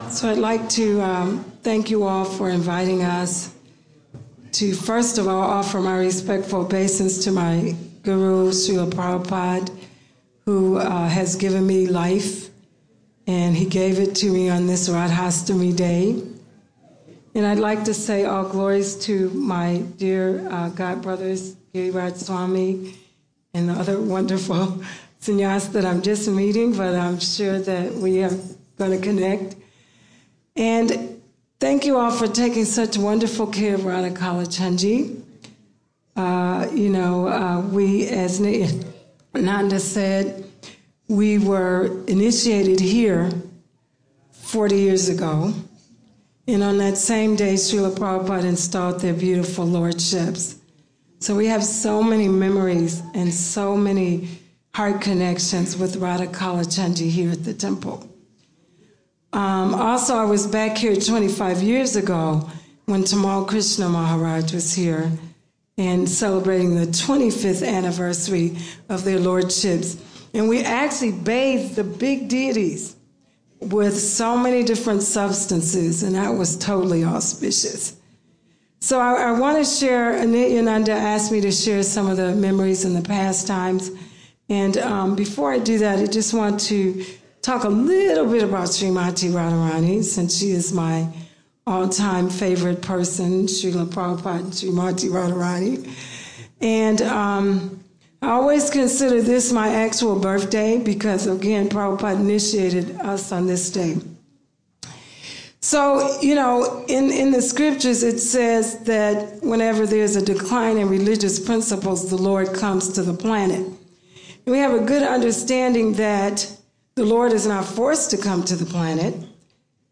Lecture - Radhastami